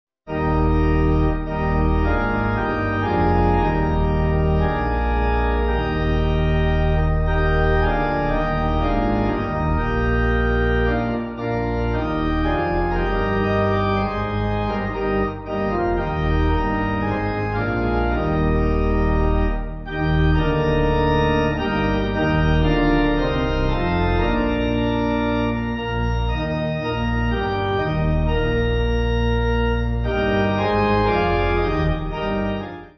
8.6.8.6.D with hallelujahs
Organ
(CM)   2/Eb